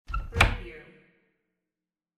Closet door open sound effect .wav #3
Description: The sound of a closet door being opened (w/slight squeak)
Properties: 48.000 kHz 16-bit Stereo
closet-door-open-preview-3.mp3